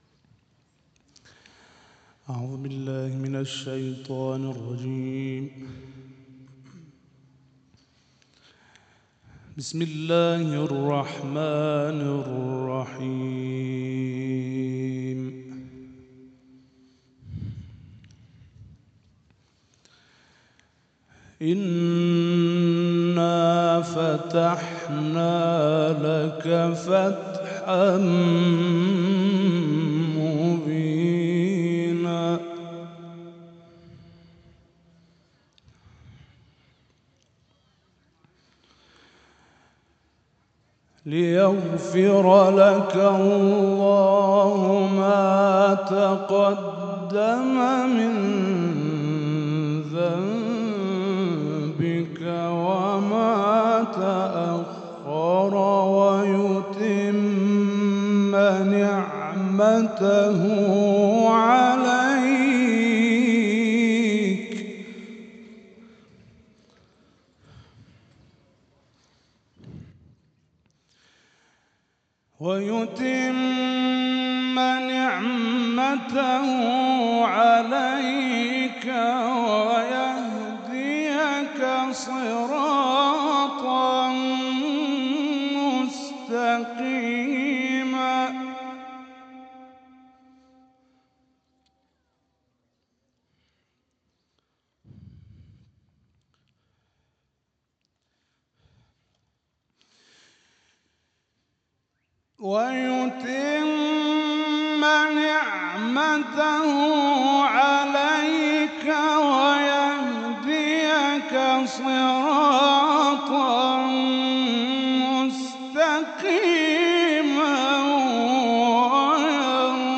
این تلاوت در محفل انس با قرآن «به سوی فتح» صورت گرفت.
این محافل قرآنی با حضور جامعه قرآنی کشور ۱۹ تیرماه در جوار مزار شهید سردار امیرعلی حاجی‌زاده، فرمانده فقید هوافضای سپاه و همزمان با تهران در گلزار شهدای دیگر شهرستان‌های کشور برپا شد.